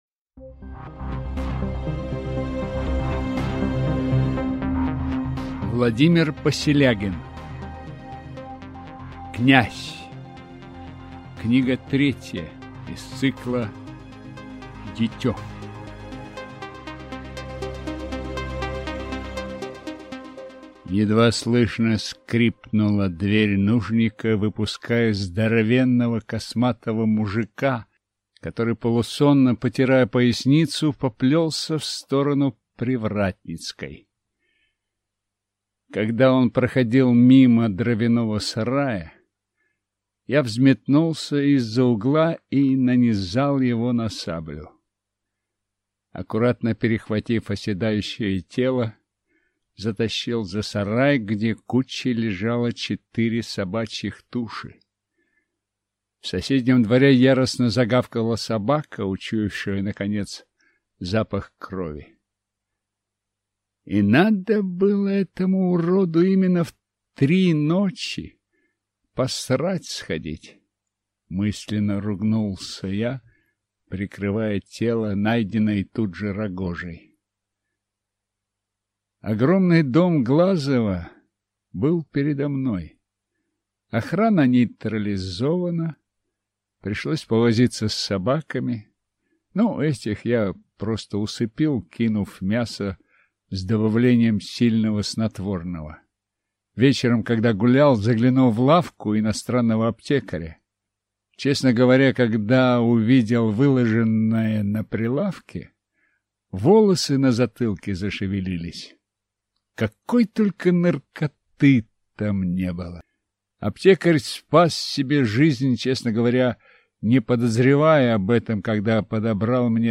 Аудиокнига Дитё. Князь | Библиотека аудиокниг